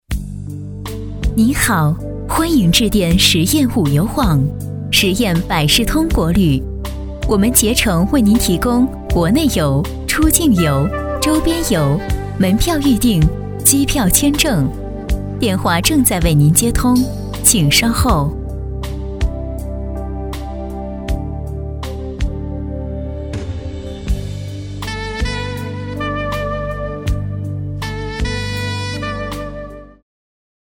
女声配音
彩铃女国95